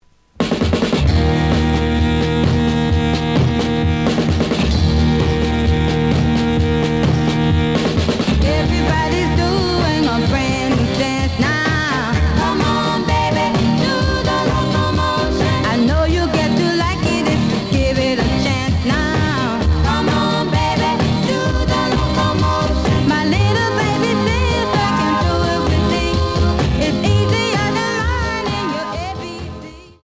試聴は実際のレコードから録音しています。
●Genre: Rock / Pop
●Record Grading: EX- (盤に若干の歪み。多少の傷はあるが、おおむね良好。)